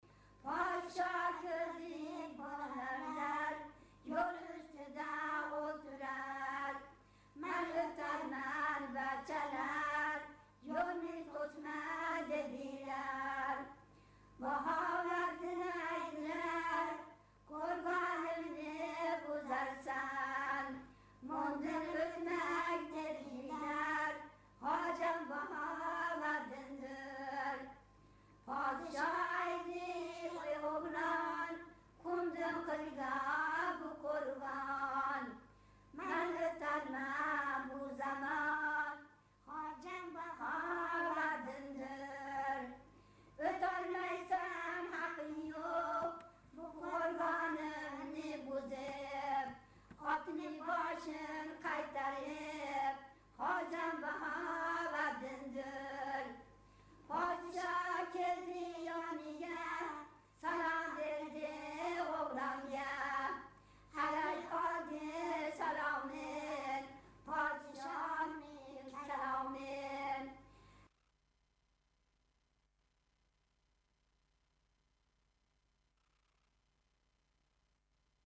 Анжияндык отинойлордун Кожо Баховатдиндинге арналган ыры
Анжиянда